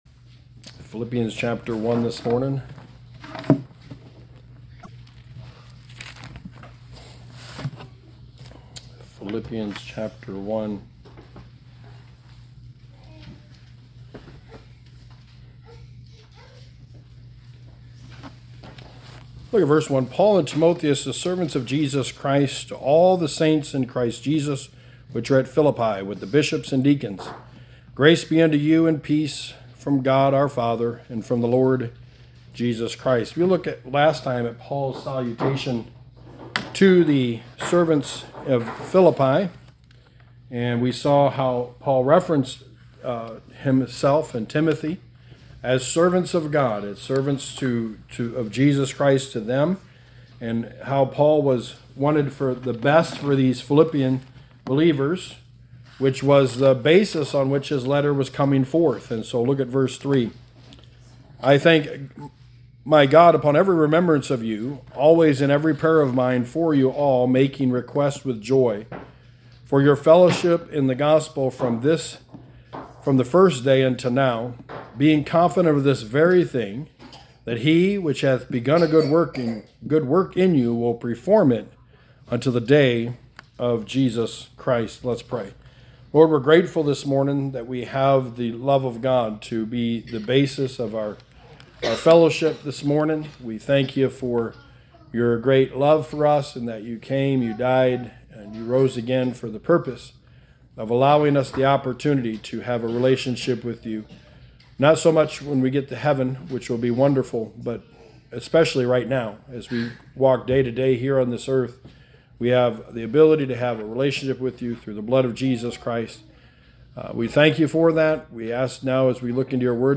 Sermon 4: The Book of Philippians: The Need for Friends